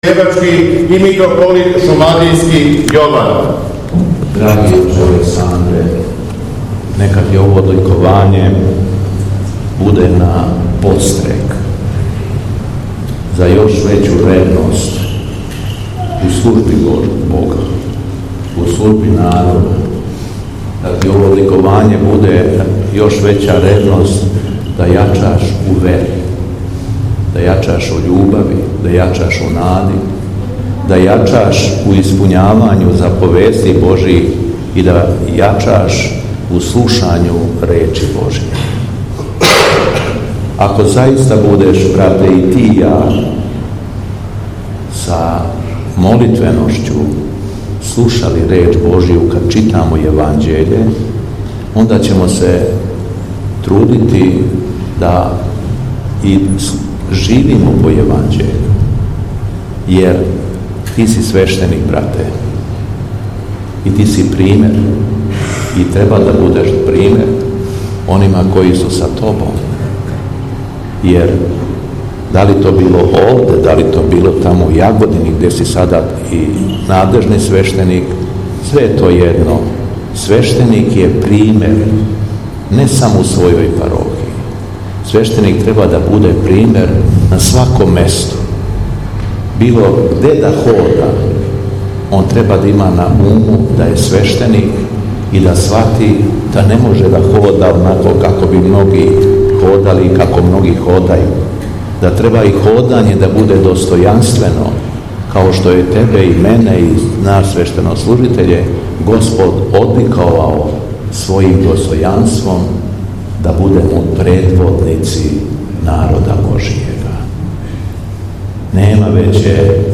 Након прочитаног јеванђелског зачала Архијереј је надахнуто беседио рекавши:
Беседа Његовог Високопреосвештенства Митрополита шумадијског г. Јована